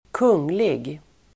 Uttal: [²k'ung:lig]
kunglig.mp3